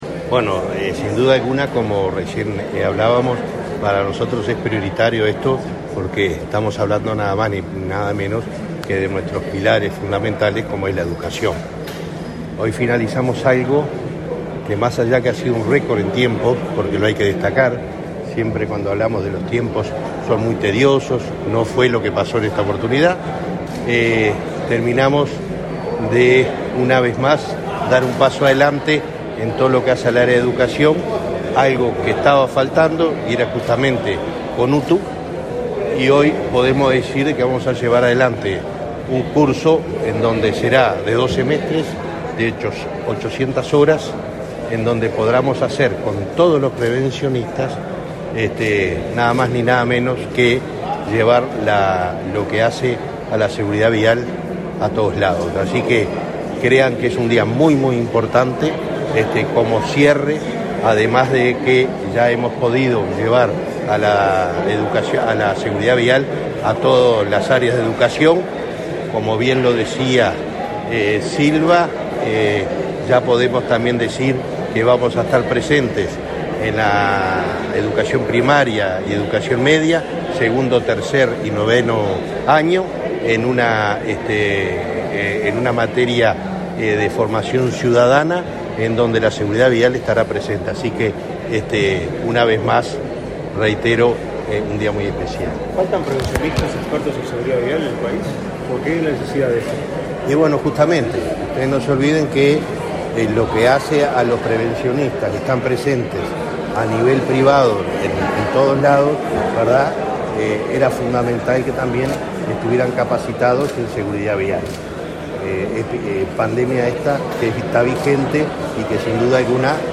Declaraciones a la prensa del presidente de la Unasev, Alejandro Draper
Tras el evento, Draper realizó declaraciones a la prensa.